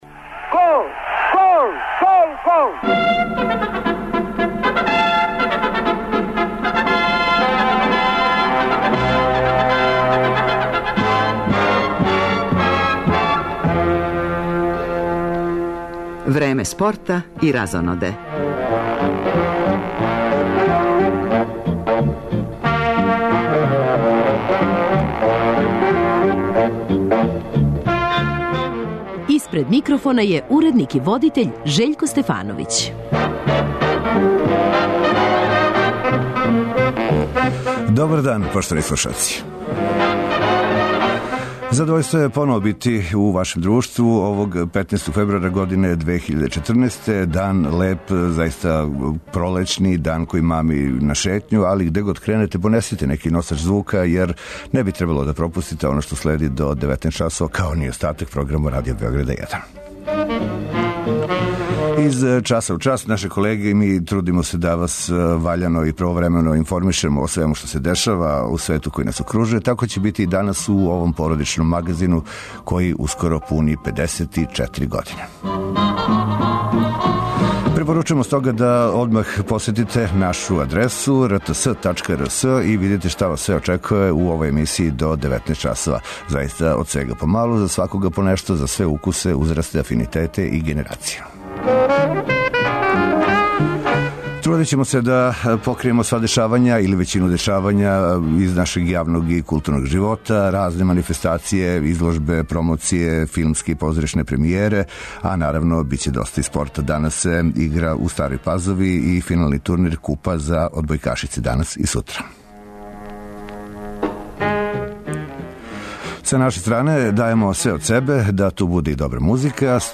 У емисији ћете ексклузивно чути Љубинка Друловића, први пут као вршиоца дужности селектора фудбалске репрезентације Србије.
У редовној рубрици о незаборавним спортским асовима, данас нам је саговорник Миодраг Белодедић, у чијој биографији стоји да је два пута освајао Куп европских шампиона у фудбалу, најпре са румунском Стеауом, потом и са нашом Црвеном звездом.